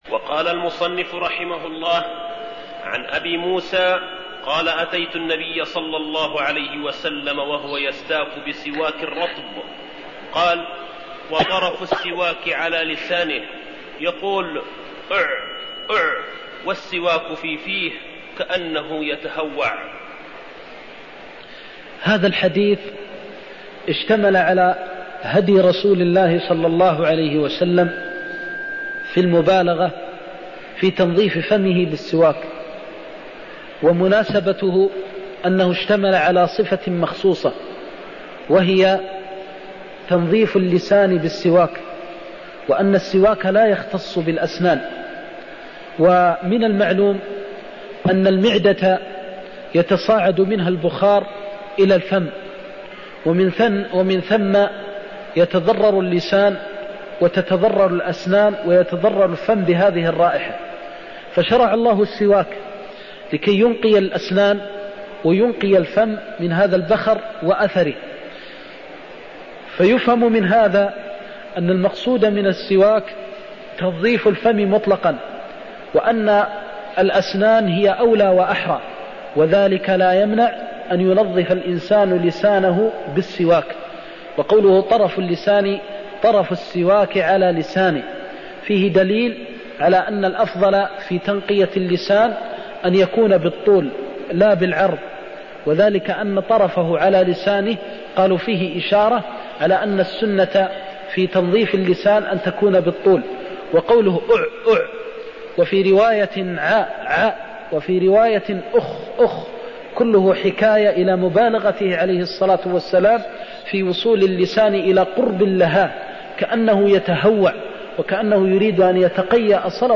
المكان: المسجد النبوي الشيخ: فضيلة الشيخ د. محمد بن محمد المختار فضيلة الشيخ د. محمد بن محمد المختار هدي النبي صلى الله عليه وسلم في صفة الإستياك (21)